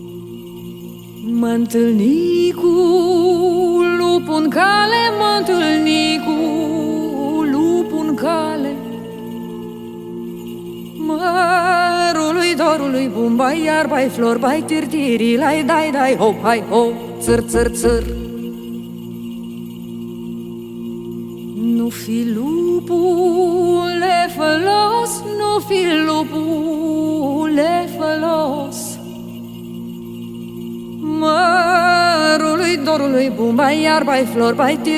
Жанр: Фолк-рок
# Traditional Folk